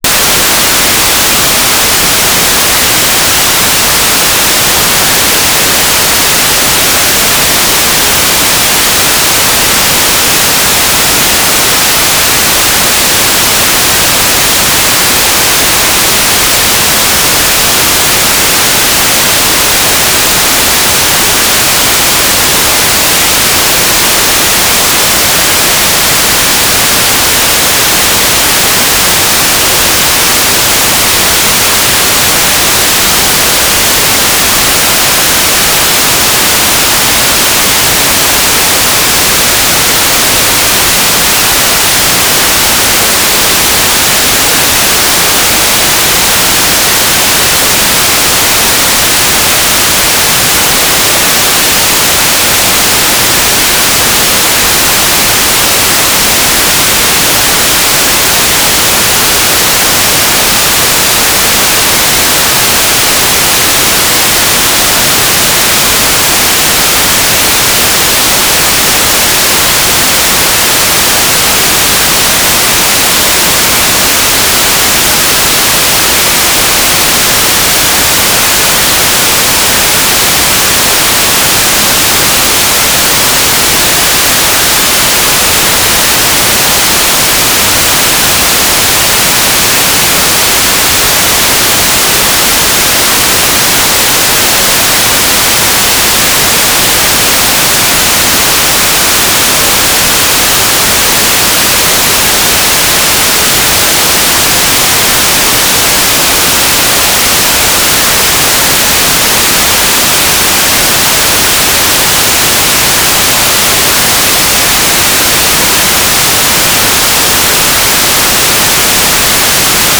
"transmitter_description": "Mode U - GMSK 9k6 (USP) TLM",
"transmitter_mode": "GMSK USP",